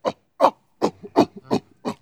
c_goril_slct.wav